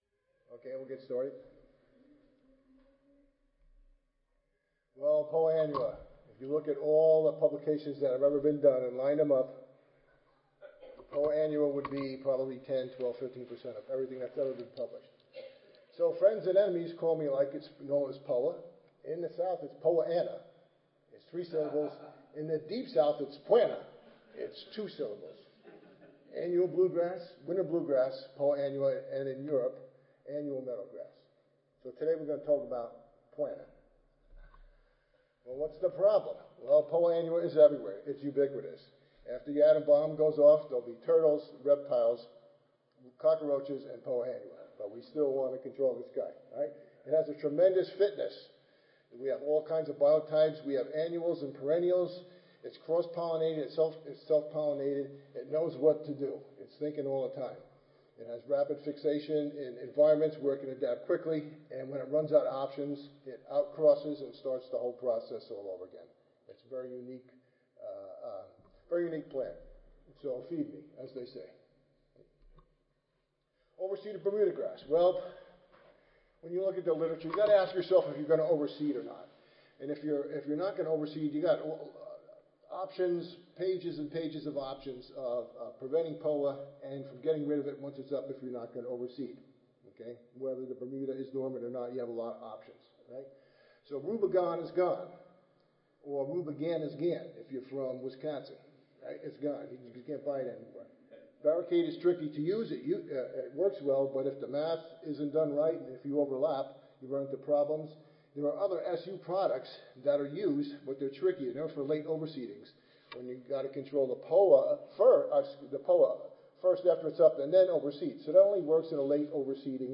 Univ Arizona Audio File Recorded Presentation